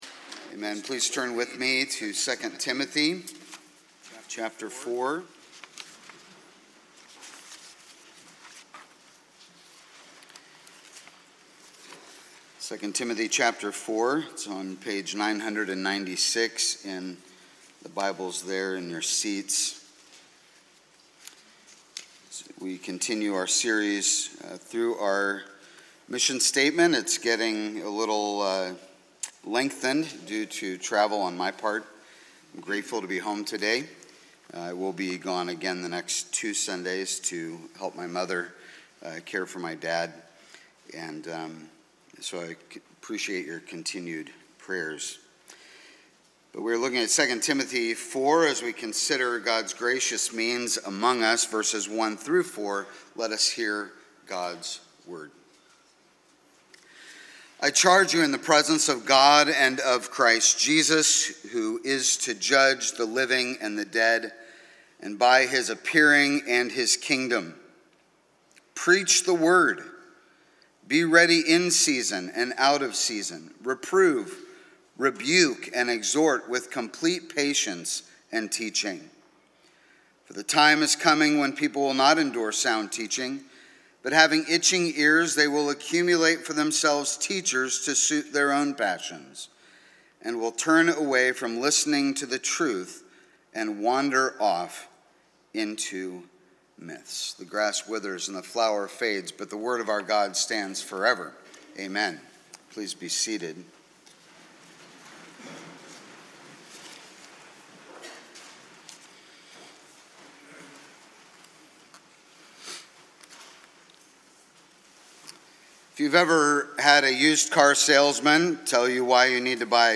*NOTE: The sermon was not recorded as an audio file; it can still be listened to through the Youtube video.